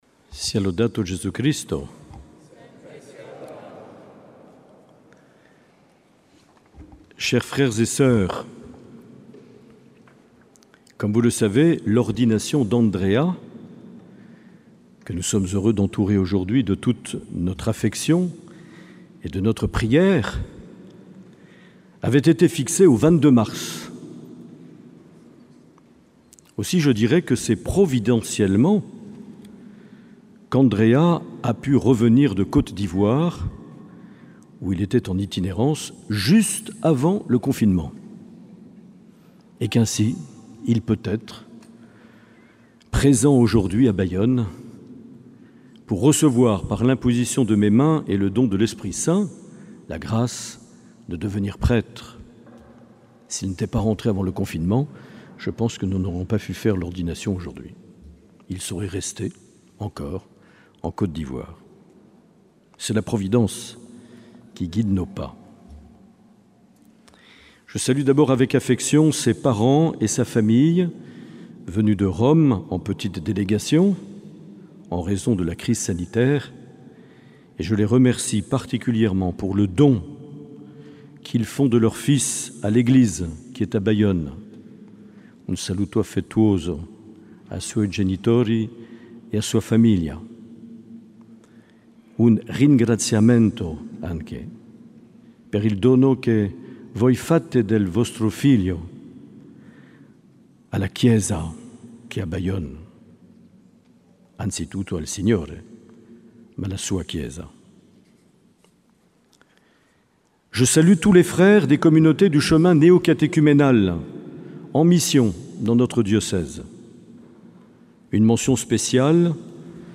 Accueil \ Emissions \ Vie de l’Eglise \ Evêque \ Les Homélies \ 27 juin 2020 - Cathédrale de Bayonne
Une émission présentée par Monseigneur Marc Aillet